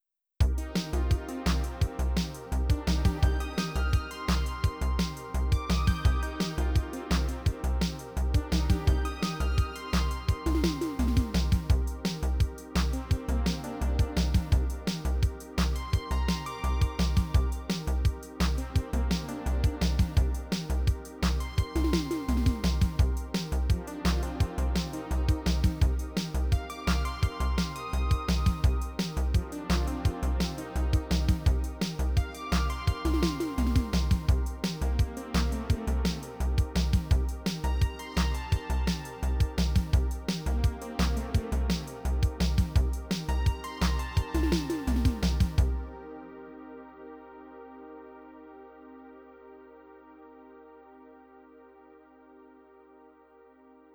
Pas un synthé a proprement parler, enfin c'est proche:
Et en plus depuis la derniere mise a jour, peu faire de la FM!
TR-8S, melange de samples, VA emulant les vielles boite a rythme de chez Roland tel que le TR-808, et maintenant des son FM qui n'existent dans aucune autre BaR grin